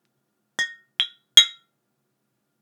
• cheers bottle three Ab Bb.wav
cheers_bottle_three_Ab_Bb_RsL.wav